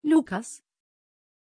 Aussprache von Loukas
pronunciation-loukas-tr.mp3